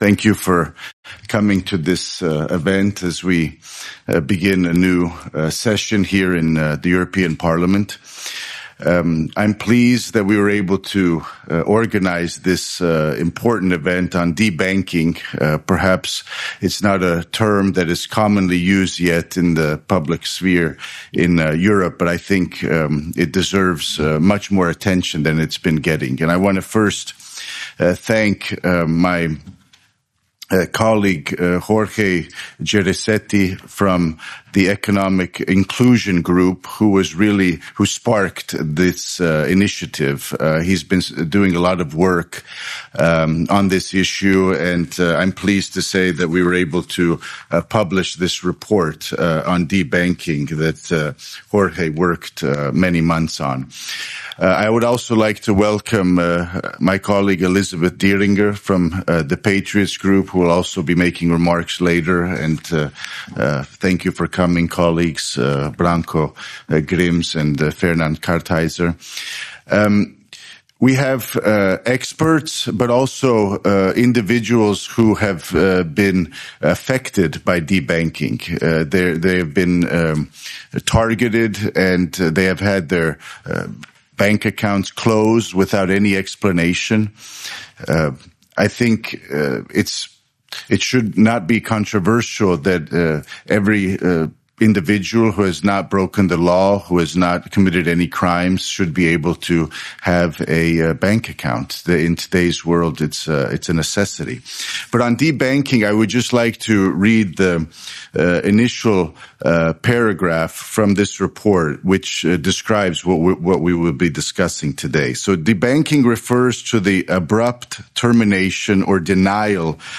That was the debanking conference in the European Parliament in Brussels.